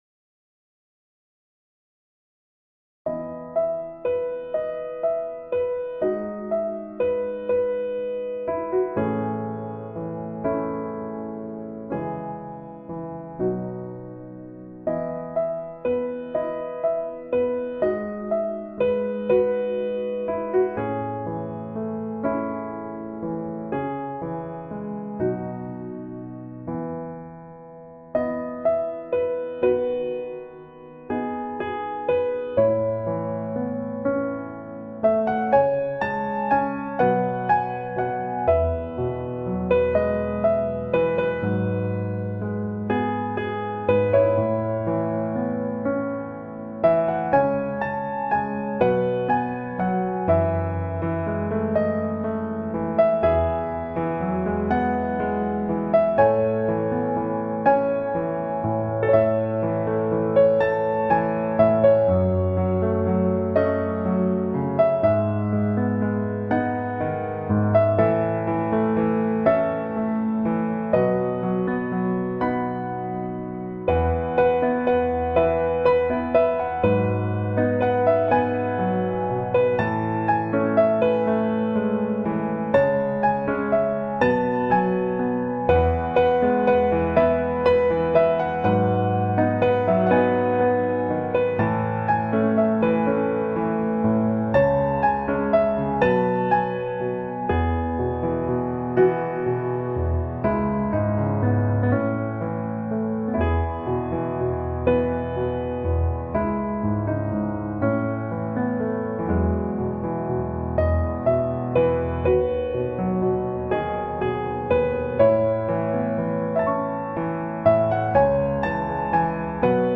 piano cover